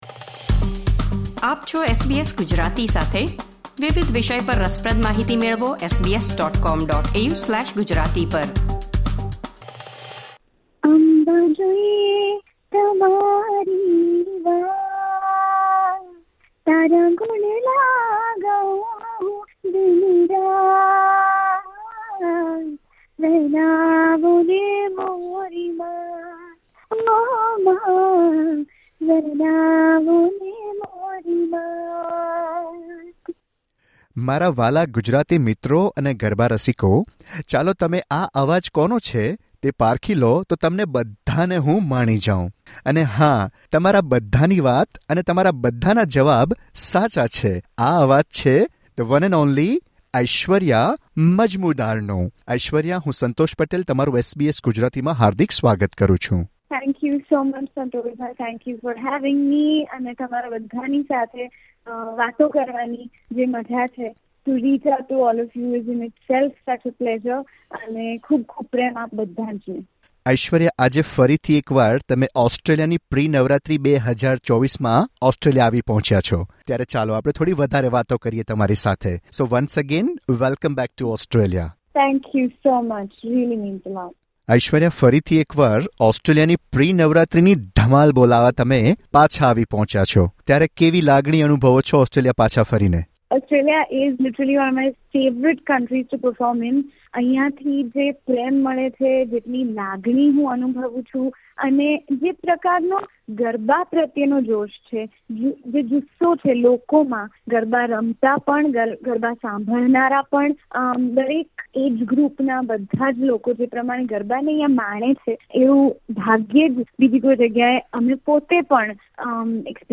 તેમણે SBS Gujarati સાથેની વાતચીતમાં નવરાત્રી, સંગીત કારકિર્દી તથા ઓસ્ટ્રેલિયામાં ગરબા કાર્યક્રમો પ્રસ્તુત કરવા વિશે વાત કરી હતી.
Gujarati singer Aishwarya Majmudar.